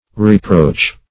Reproach \Re*proach"\ (r?-pr?ch"), v. t. [imp. & p. p.